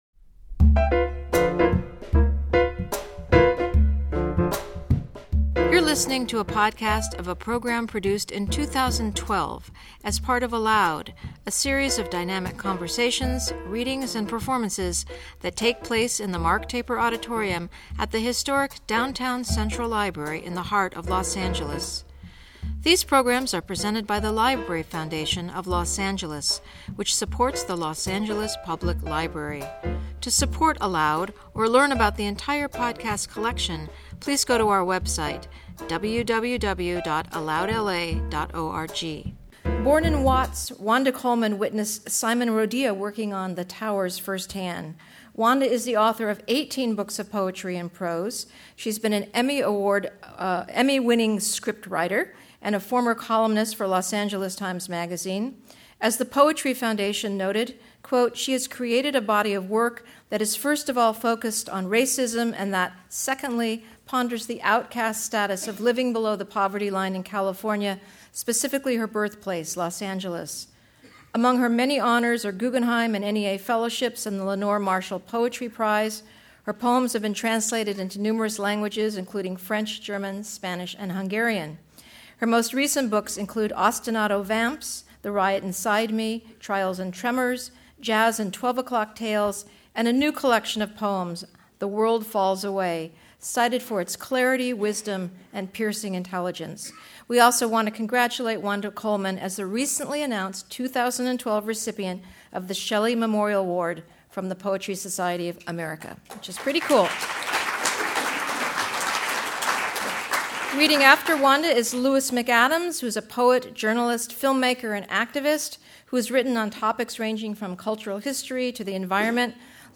Two celebrated poets read from their most recent work and discuss how Los Angeles has influenced their writing, how some influences overlap and others diverge.